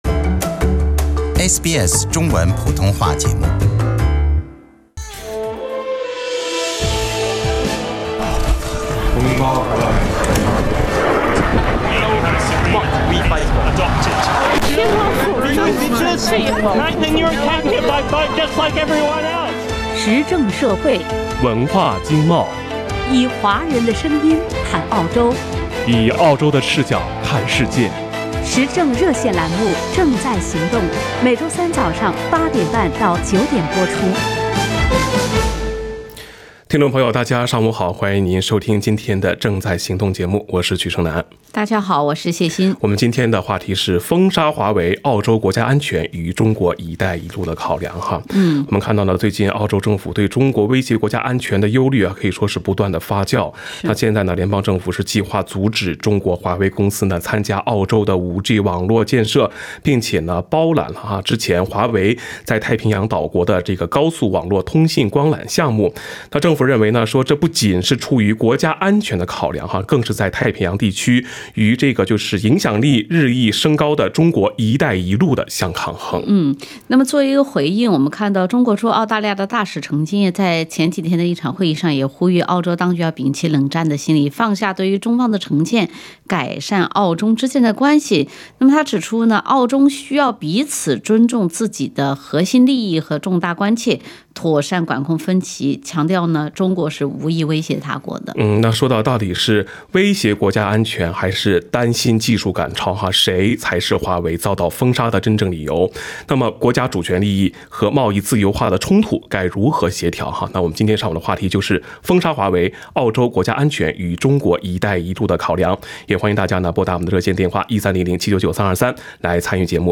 时政热线节目《正在行动》逢周三上午8点30分至9点播出。